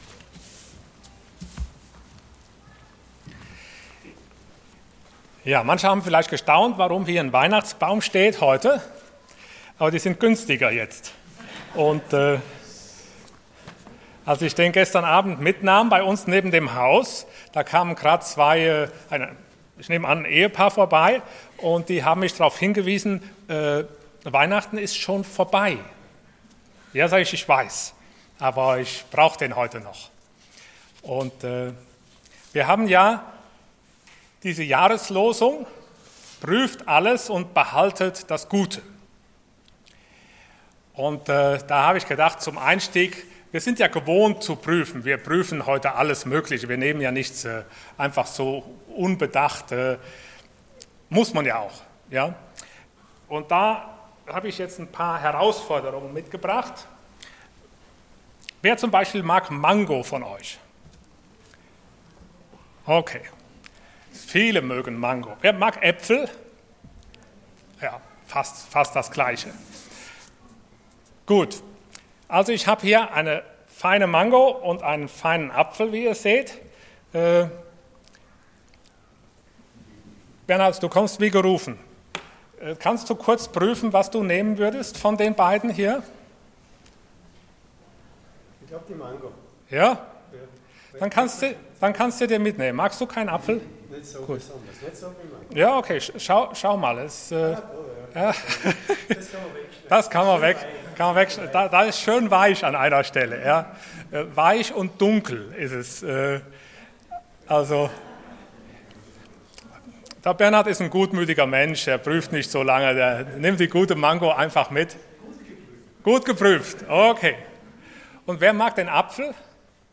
Allgemeine Predigten Passage: 1. Thessalonicher 5,16-22 Dienstart: Sonntag Morgen Prüft alles und behaltet das Gute Themen: Jahreslosung « Warum Mensch?